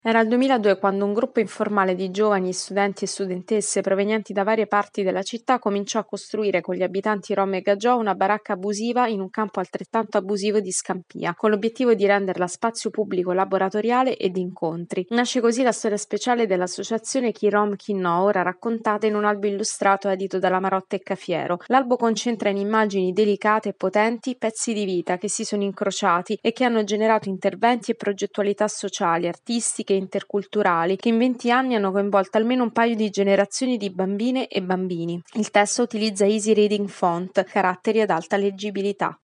Storie di integrazione. Sarà presentato sabato 6 novembre “Chi rom e…chi no”, albo illustrato ispirato alla storia dell’omonima associazione di Scampia.